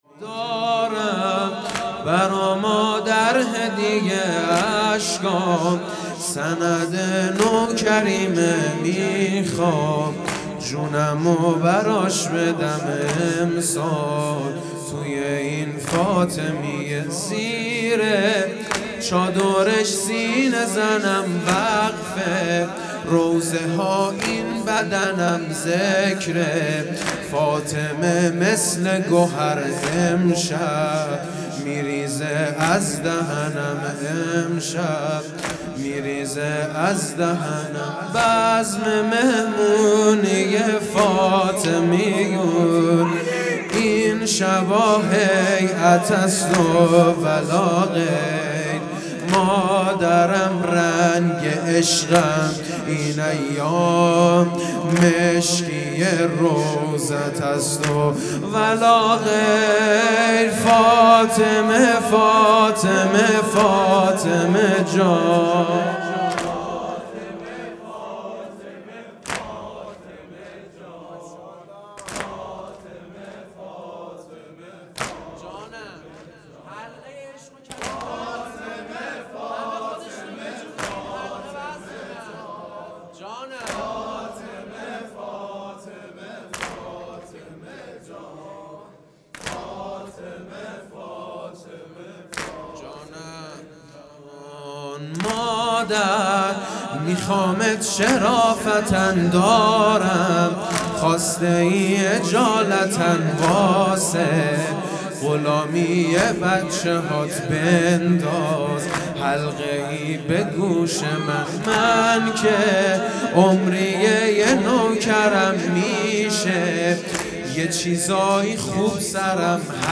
سند نوکری|شهادت حضرت زهرا (س) ۹۶